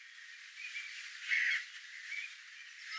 Rannikolla äänitetty.